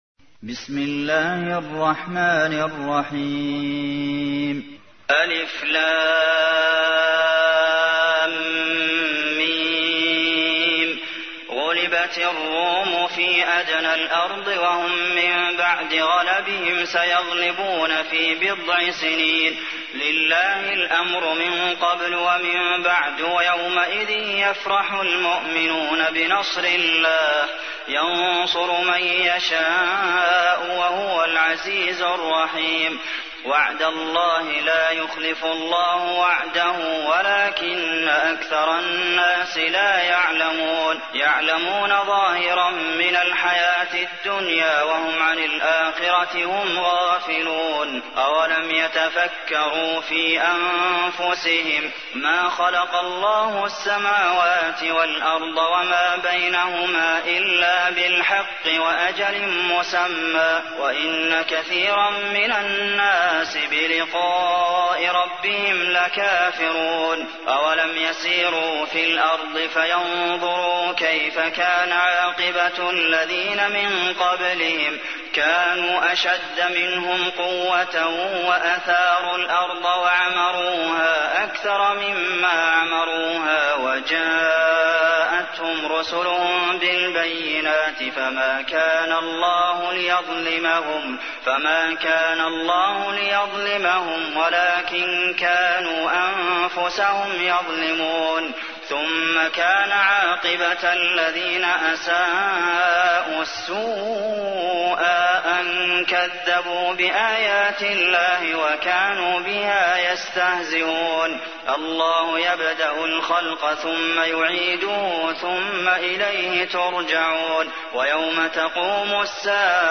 تحميل : 30. سورة الروم / القارئ عبد المحسن قاسم / القرآن الكريم / موقع يا حسين